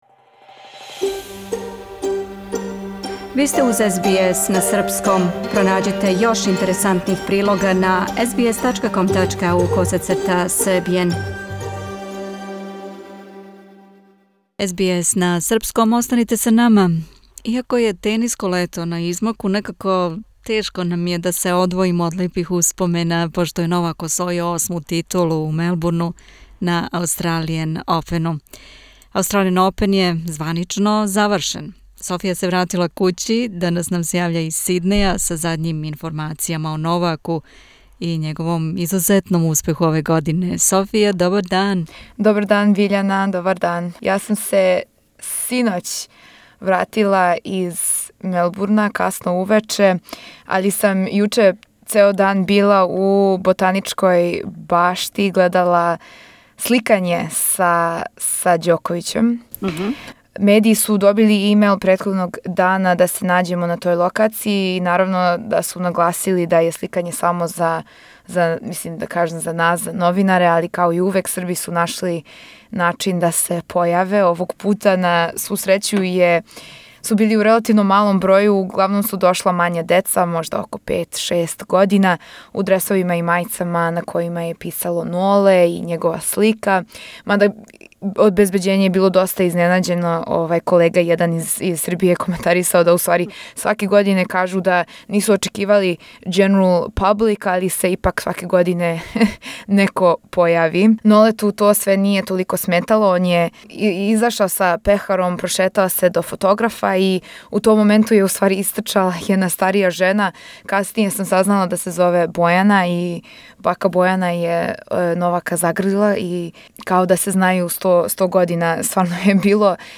Posing with the winner's Cup, Novak Djoković is the eight time winner of the Australian Open and once again, the number one ranked tennis player in the world. SBS Serbian caught up with him after his official photoshoot.